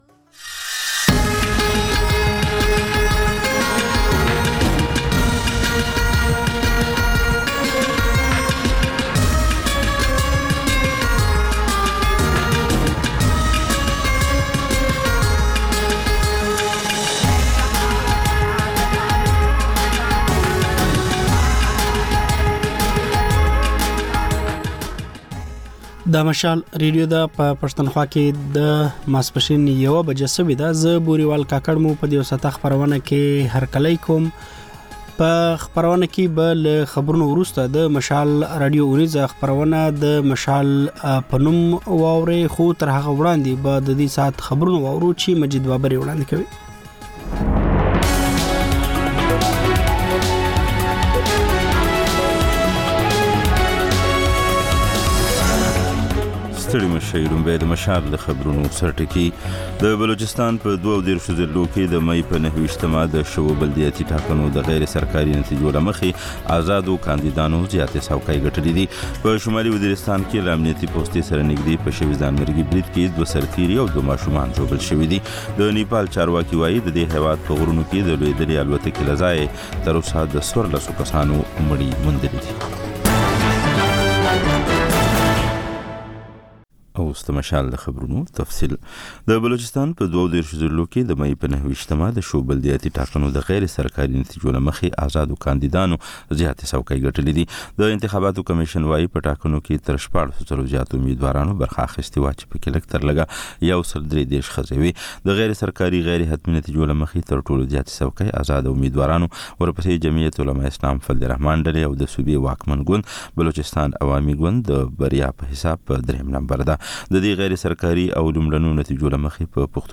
د مشال راډیو لومړۍ ماسپښينۍ خپرونه. په دې خپرونه کې تر خبرونو وروسته بېلا بېل رپورټونه، شننې، مرکې خپرېږي. په دې ګړۍ کې اوونیزه خپرونه هم خپرېږي.